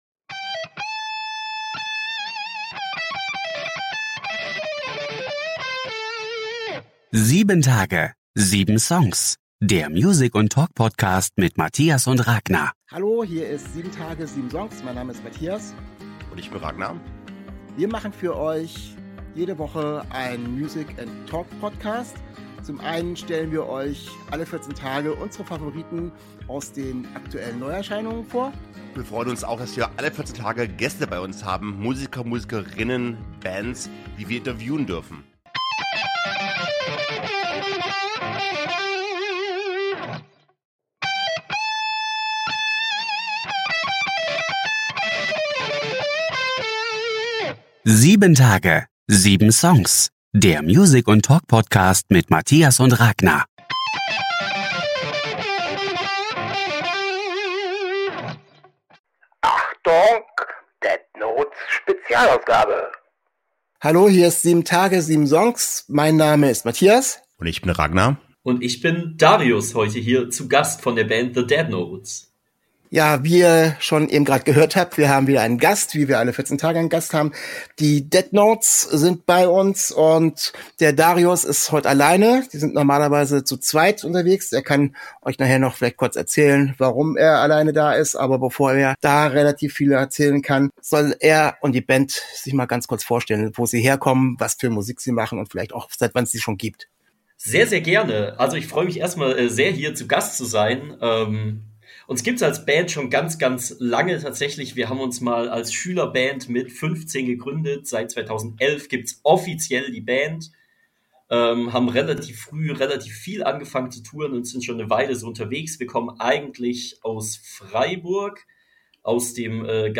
Wir sprechen über Ihre aktuellen Tour und unsere Vorfreude, sie bald live in Kassel zu sehen. In diesem Interview geht es neben ihren musikalischen Wurzeln, der Freude an Live Auftritten auch wieder um Mental Health Themen, es wird wieder einmal tiefgängig.